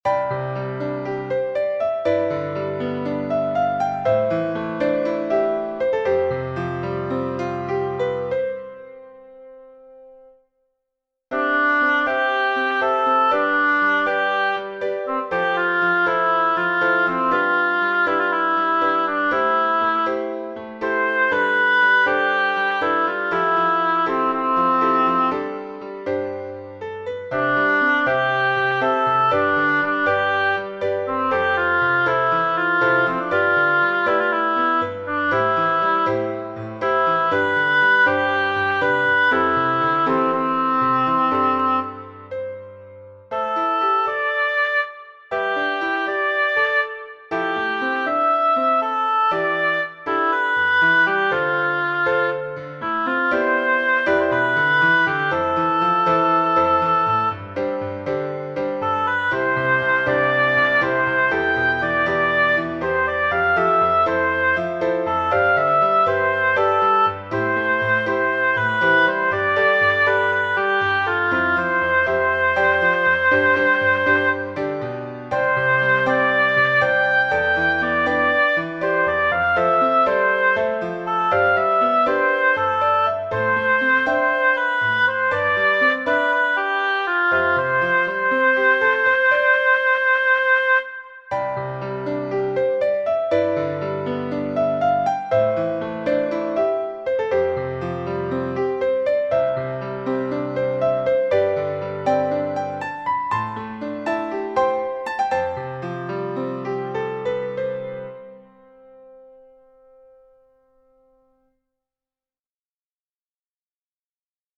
私は委員長をやってくれた学生と以下の自作曲を演奏した．